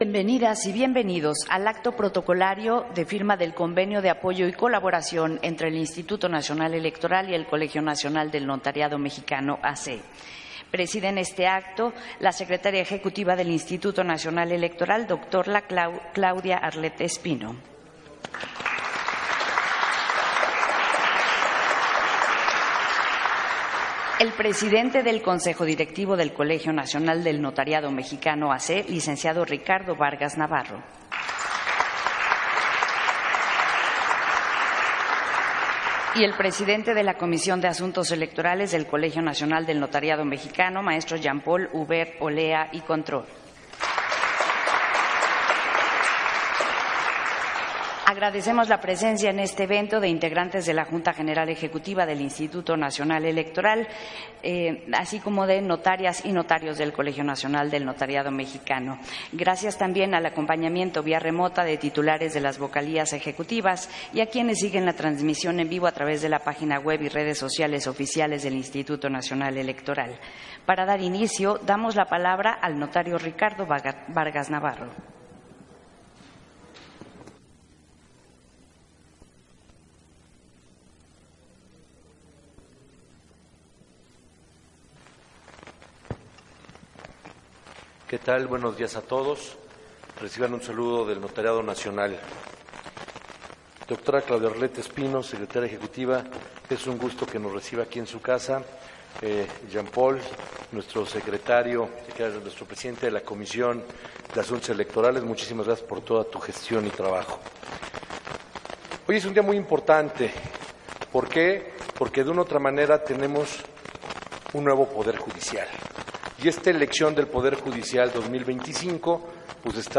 Acto protocolario de firma de convenio de apoyo y colaboración entre INE y el Colegio Nacional del Notariado Mexicano